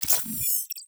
Robotic Game Notification 7.wav